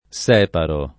separare v.; separo [Sep#ro; meno com., alla lat.,